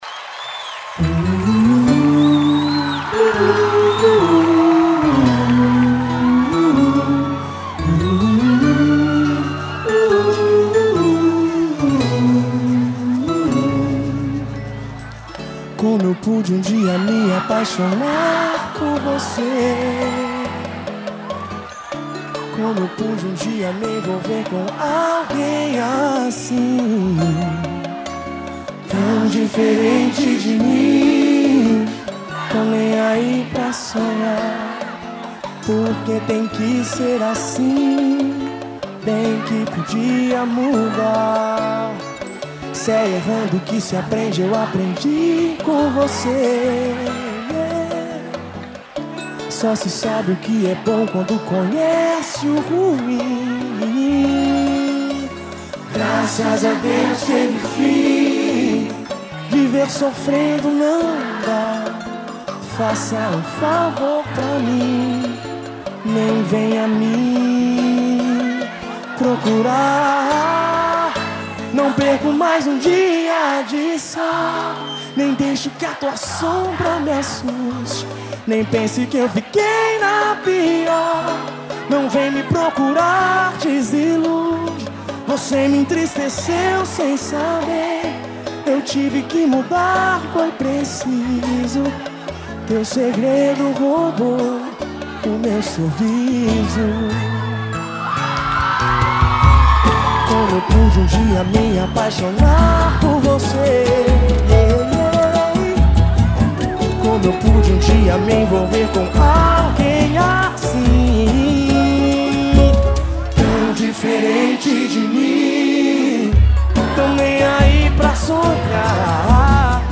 Música – ouvir online e acessar o arquivo